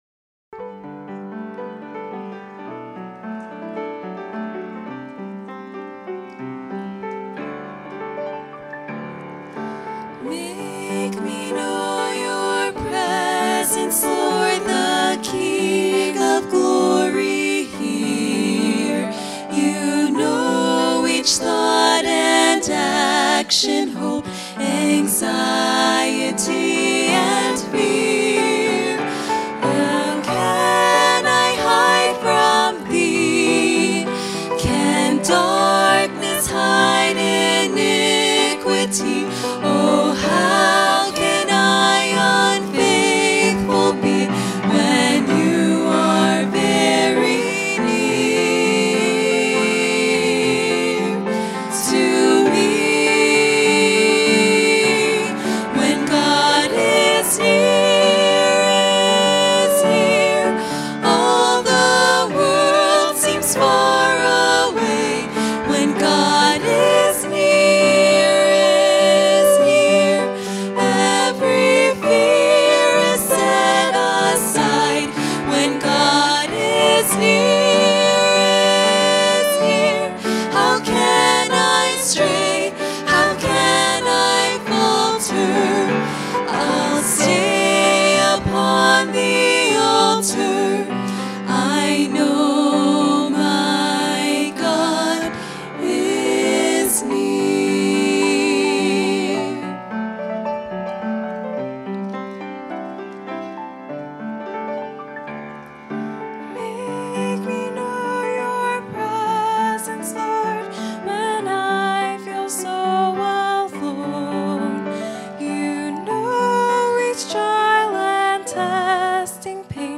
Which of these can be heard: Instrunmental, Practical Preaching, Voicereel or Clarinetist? Practical Preaching